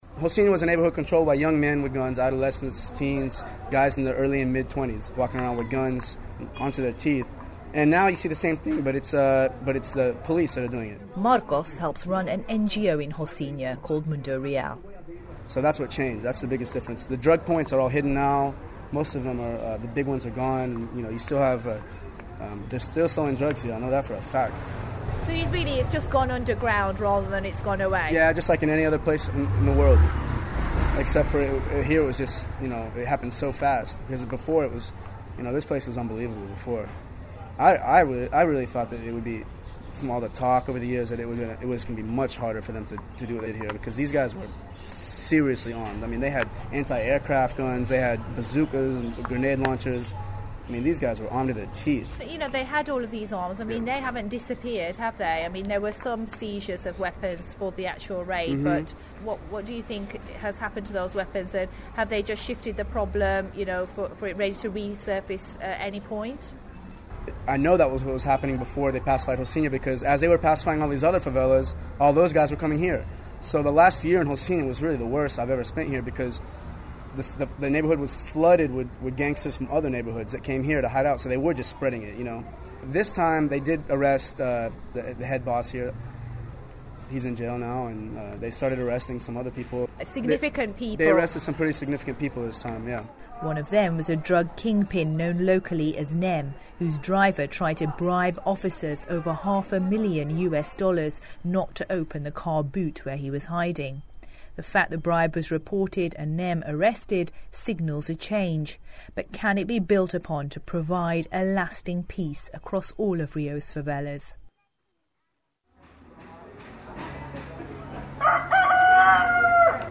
Broadcast on BBC World Service in 2012.